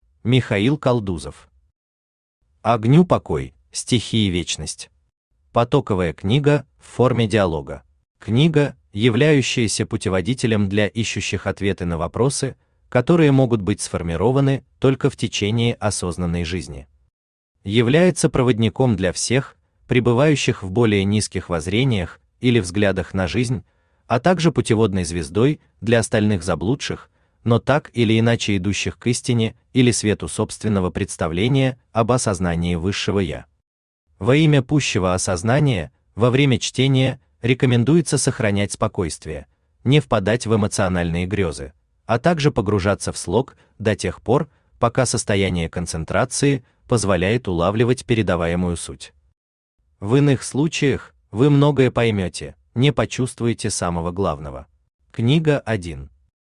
Аудиокнига Огню покой, стихии вечность.
Читает аудиокнигу Авточтец ЛитРес.